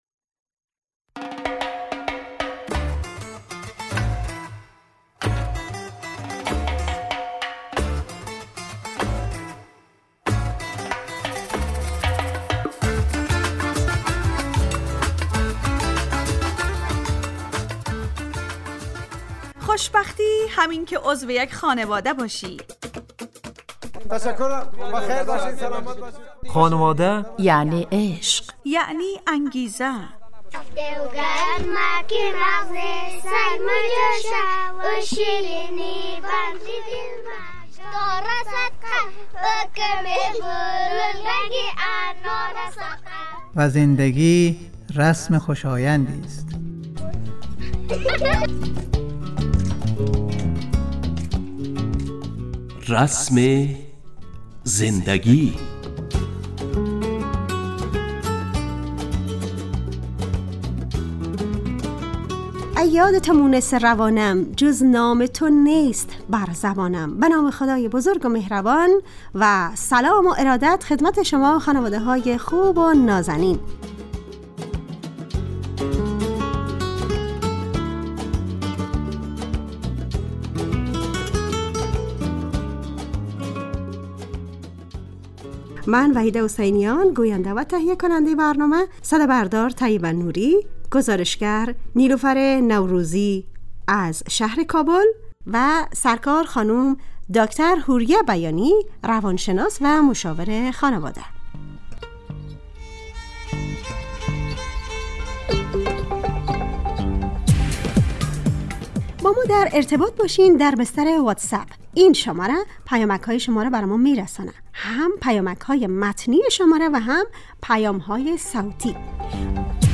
رسم زندگی_ برنامه خانواده رادیو دری ___سه شنبه 23 اردیبهشت 404 ___موضوع مهارت های اجتماعی کودکان _ نویسنده